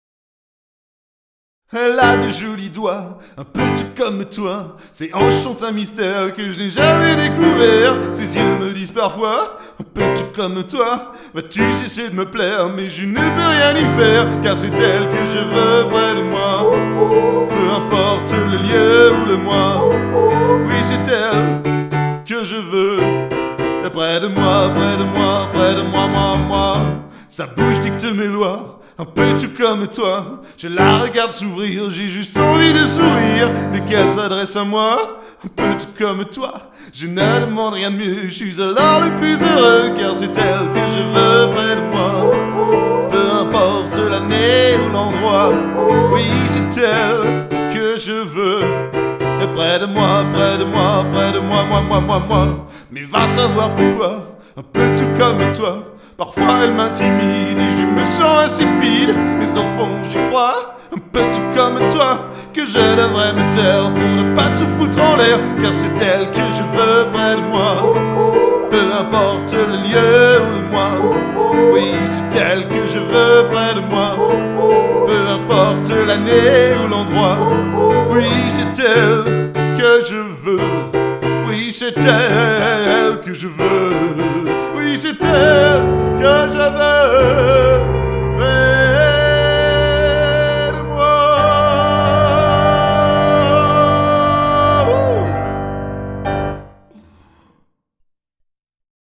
EXTRAITS MUSICAUX DU SPECTACLE